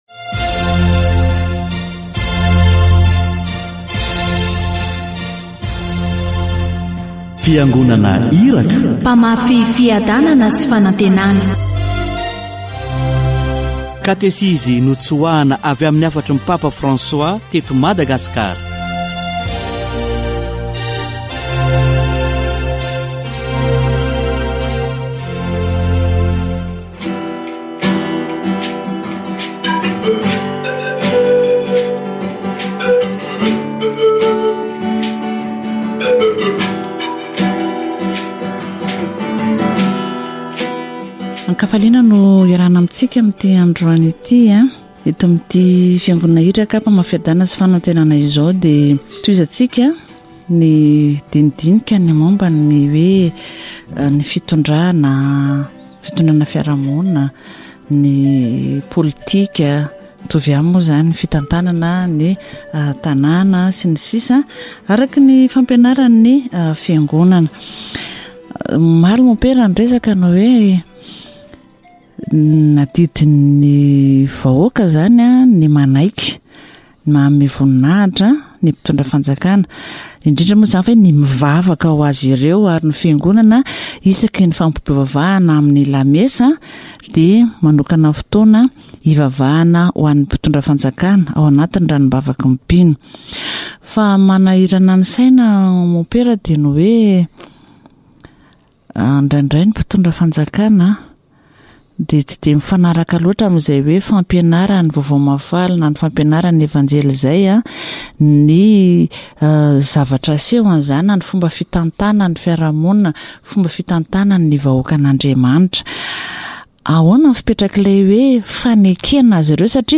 Catégorie : Approfondissement de la foi
Catéchèse sur le gouvernement et ses devoirs